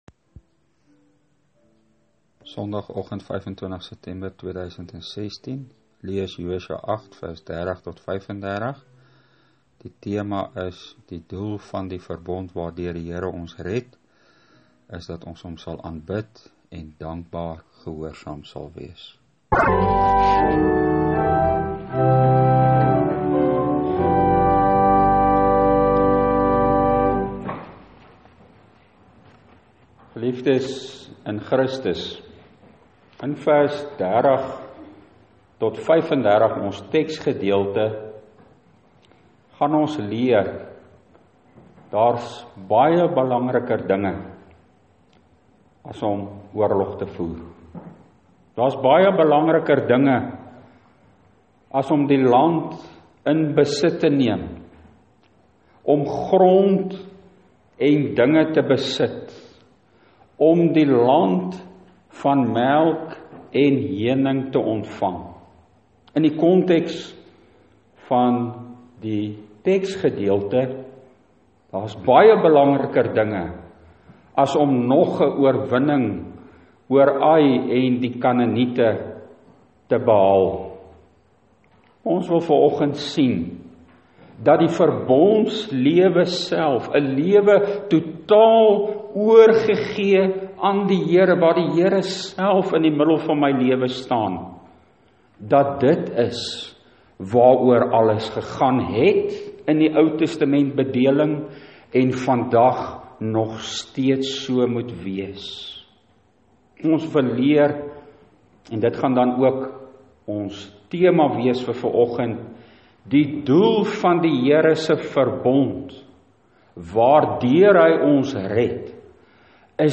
Preekopname (GK Carletonville, 2016-09-25):